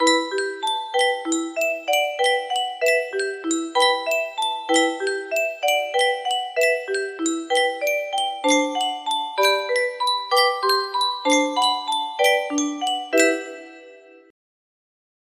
Yunsheng Music Box - We Three Kings of Orient Are Y258 music box melody
Full range 60